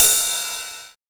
TR 909 Free sound effects and audio clips
• Ride Cymbal Sound Sample A Key 05.wav
Royality free ride one shot tuned to the A note. Loudest frequency: 7864Hz
ride-cymbal-sound-sample-a-key-05-EF2.wav